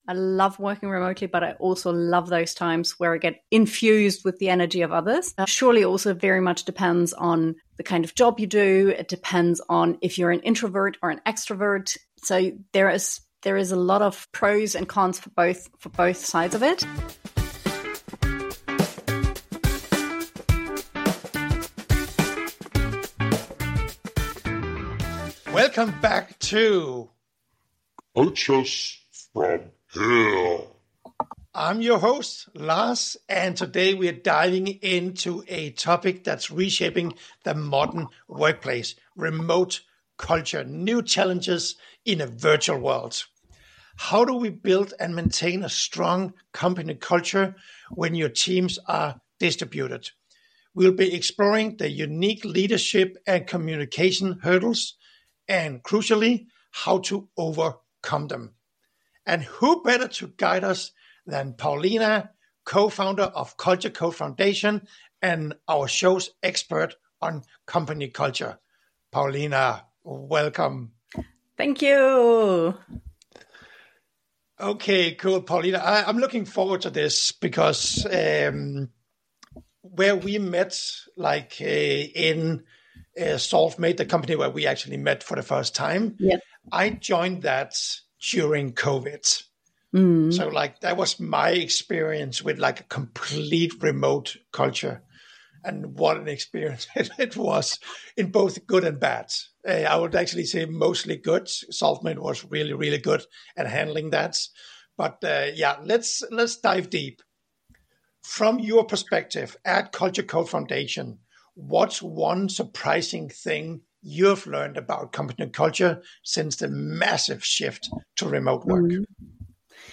It is an interactive format, where we discuss live cases anonymously and give tips and tricks both for companies and individuals experiencing these situations.